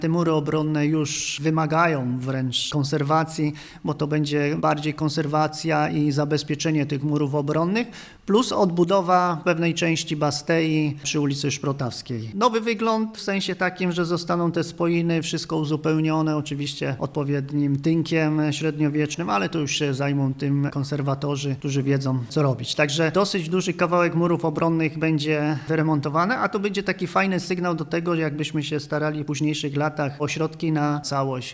– Budowle są w złym stanie i wymagają prac naprawczych – powiedział burmistrz Paweł Jagasek: